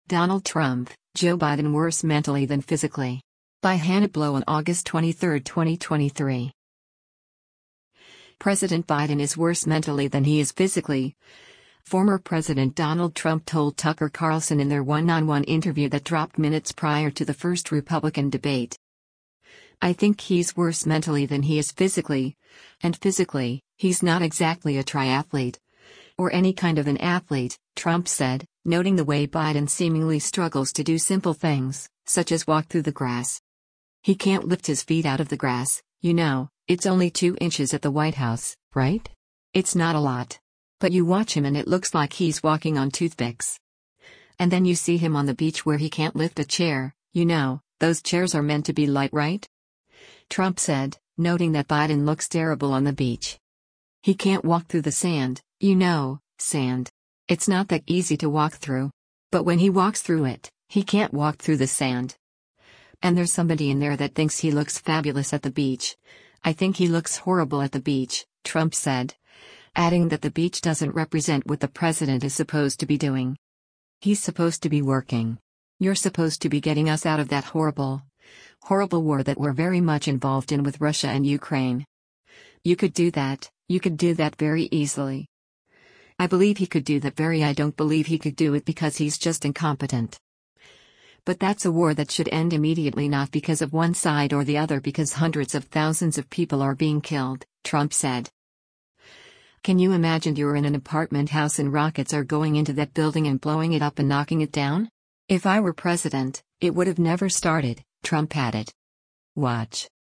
President Biden is worse “mentally than he is physically,” former President Donald Trump told Tucker Carlson in their one-on-one interview that dropped minutes prior to the first Republican debate.